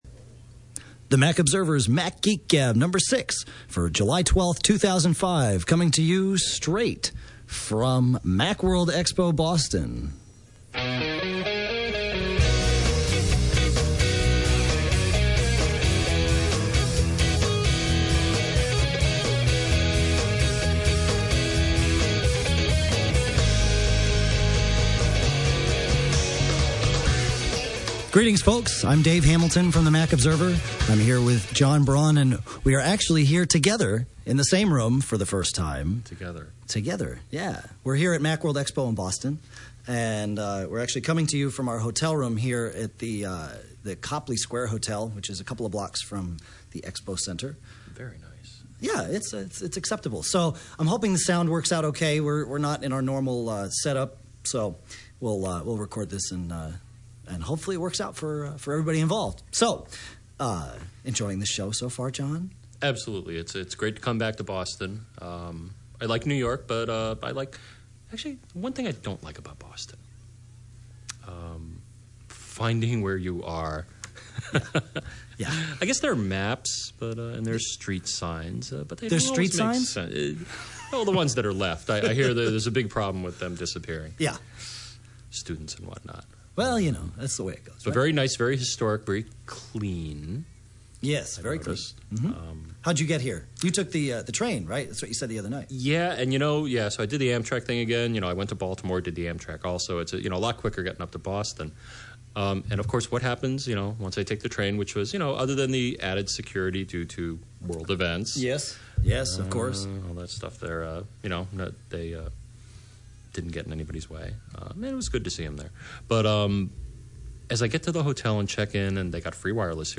Mac Geek Gab: Direct from Macworld Boston
Macworld Expo Boston is the stage for this podcast.